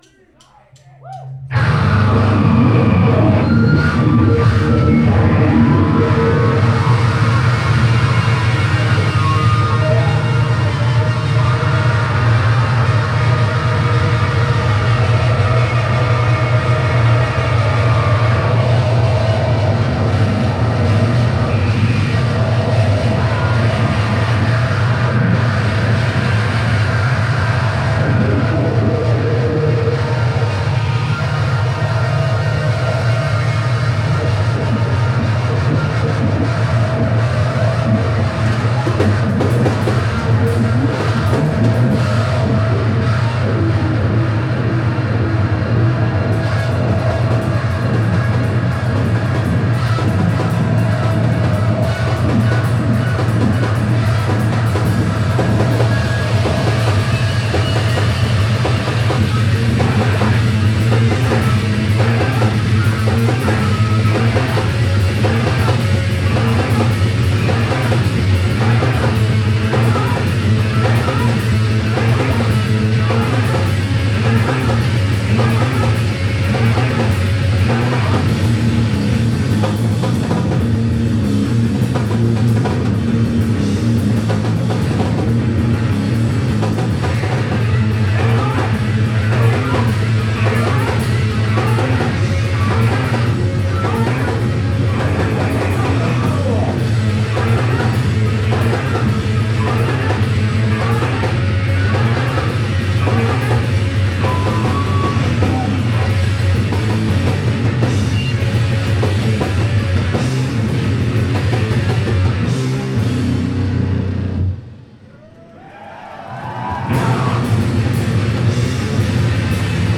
They rock and jam and shred for an hour straight.
2007-04-07 The Vera Project – Seattle, WA